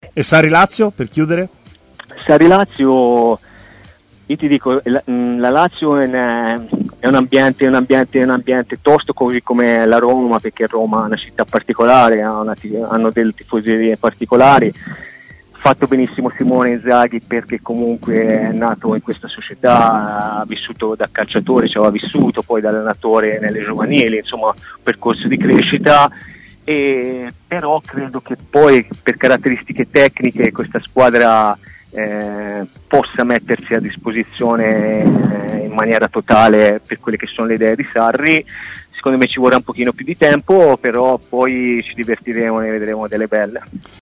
L'ex difensore, durante la sua intervista a TMW Radio, ha parlato anche dell'approdo di Sarri sulla panchina della Lazio